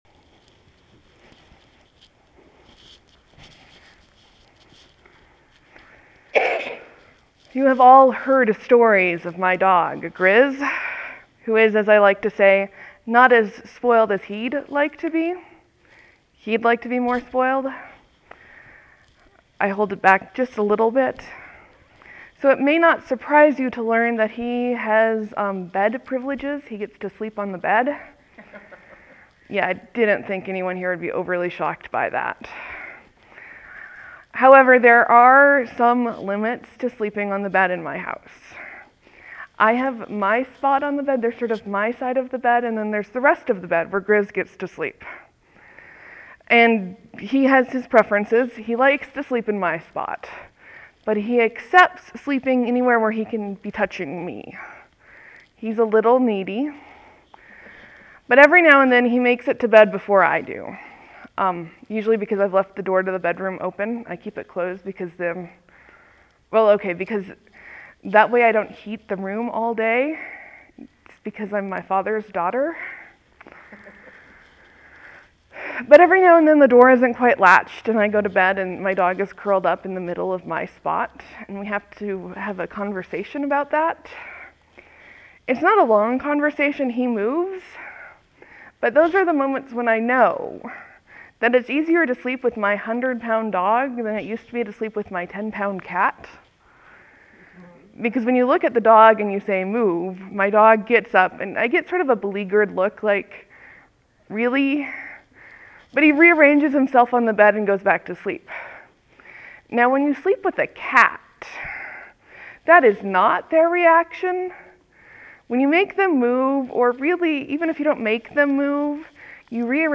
Epiphany, Sermon, , , , , Leave a comment
(There will be a few moments of silence before the sermon begins. Thank you for your patience.)